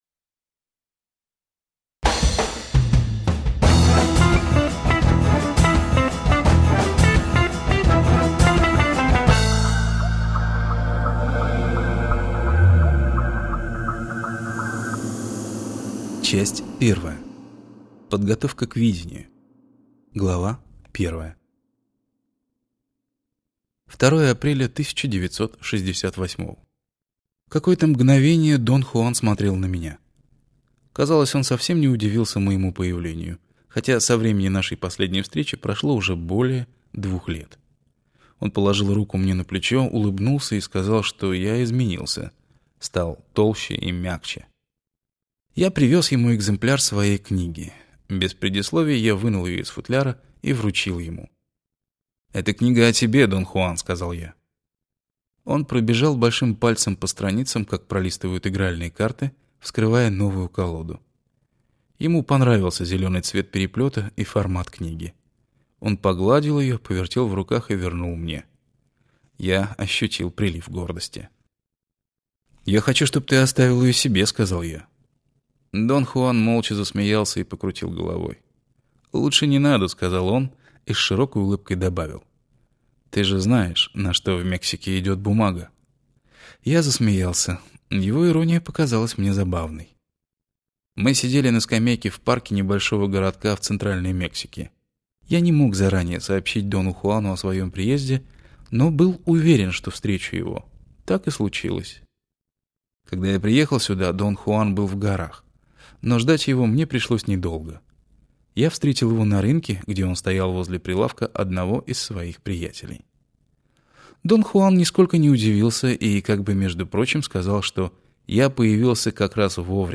Аудиокнига Отдельная реальность | Библиотека аудиокниг
Прослушать и бесплатно скачать фрагмент аудиокниги